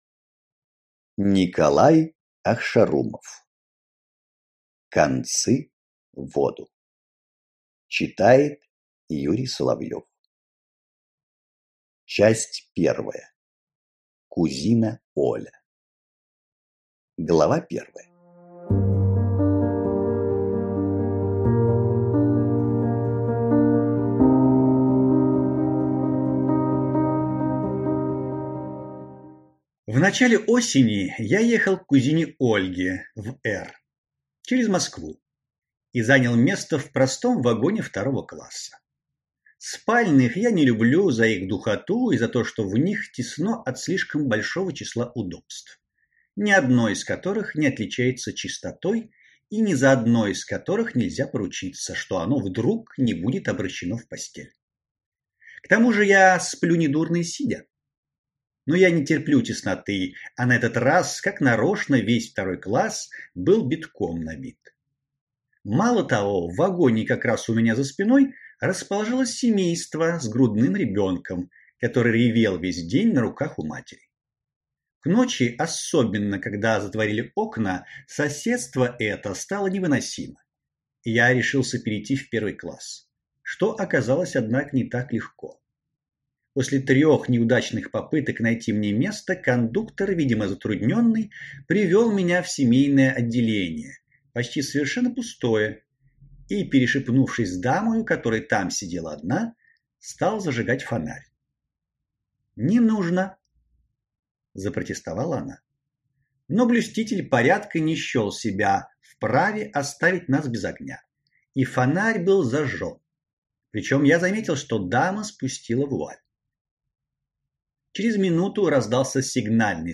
Аудиокнига Концы в воду | Библиотека аудиокниг